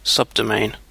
Ääntäminen
Synonyymit unqualified hostname domain name Ääntäminen UK : IPA : [sʌb.dəʊ.meɪn] Haettu sana löytyi näillä lähdekielillä: englanti Käännöksiä ei löytynyt valitulle kohdekielelle.